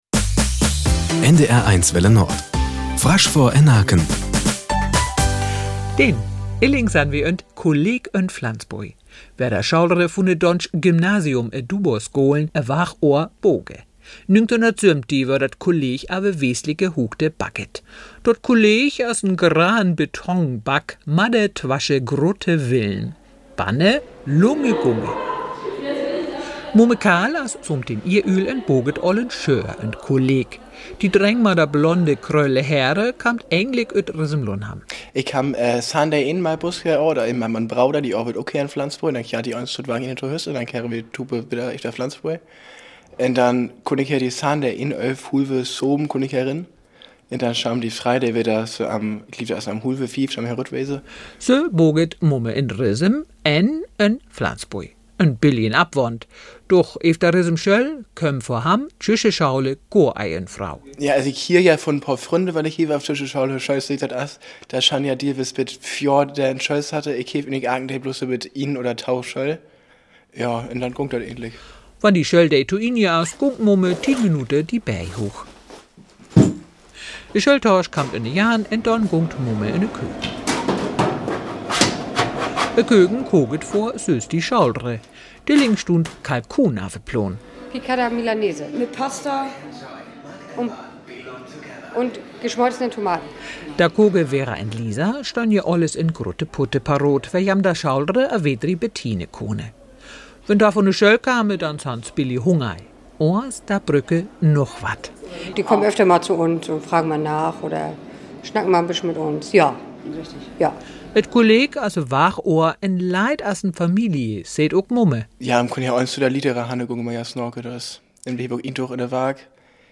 Nachrichten 09:00 Uhr - 18.04.2024